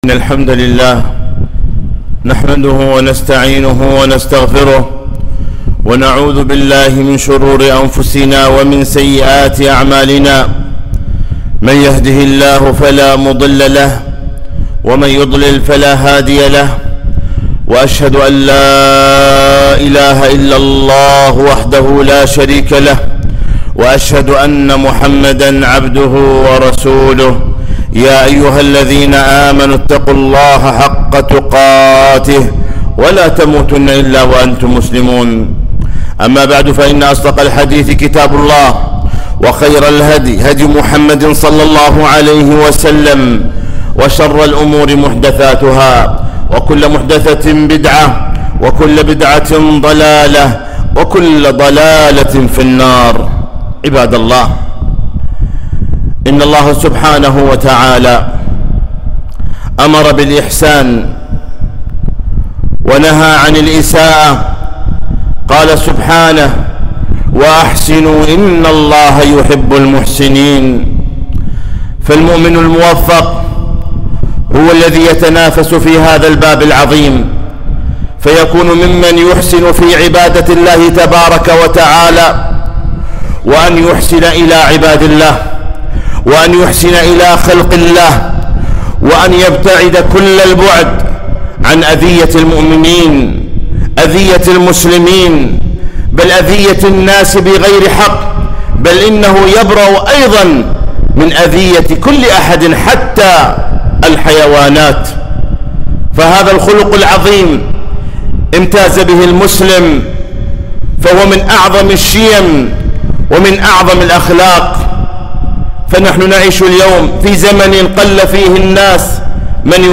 خطبة - كف الأذى عن الناس - دروس الكويت